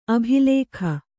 ♪ abhilēkha